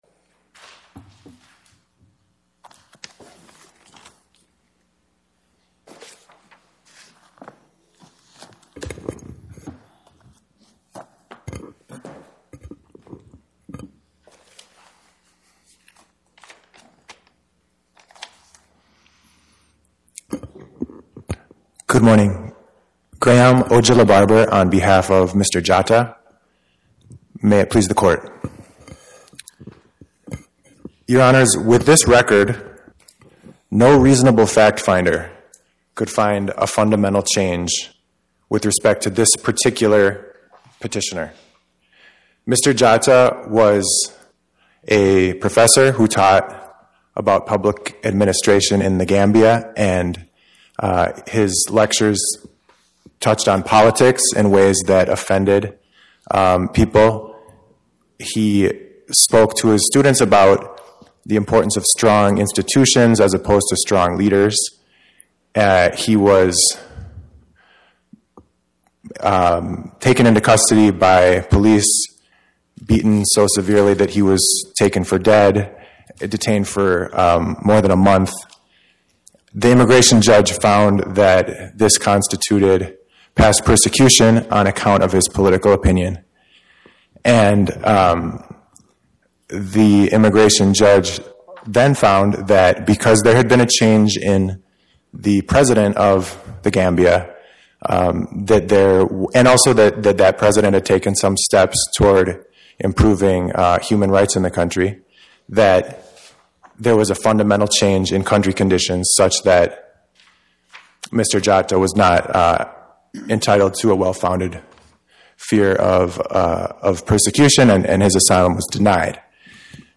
Oral argument argued before the Eighth Circuit U.S. Court of Appeals on or about 12/18/2025